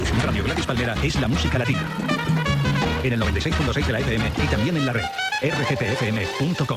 Identificació de l'emissora amb adreça web